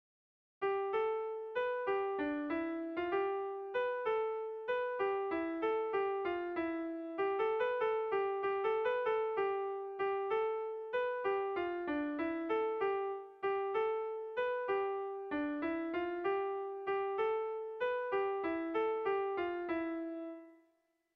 Melodías de bertsos - Ver ficha   Más información sobre esta sección
Kontakizunezkoa
A1A2BA1A2